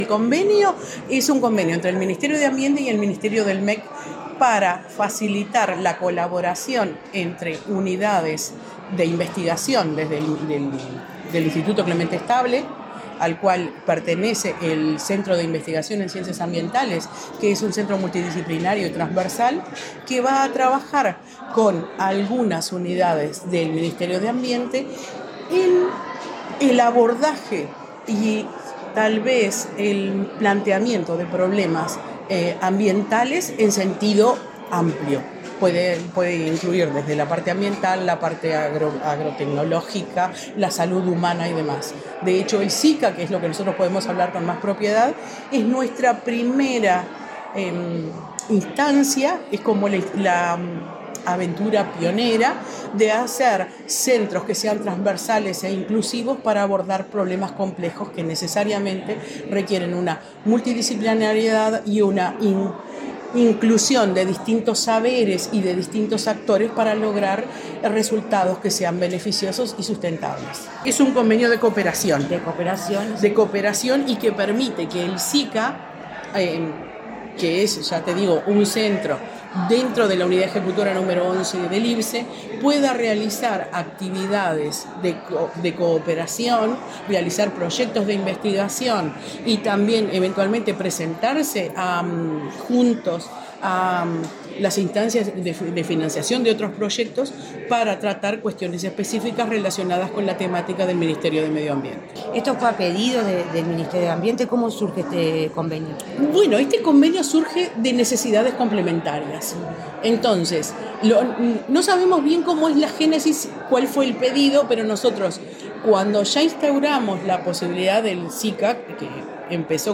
Declaraciones de la presidenta del IIBCE, Silvia Olivera